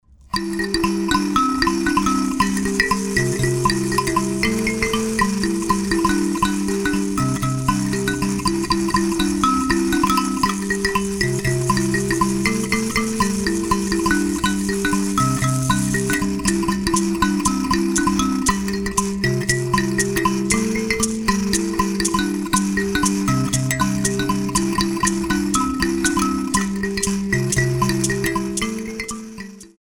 Kalimba & other instruments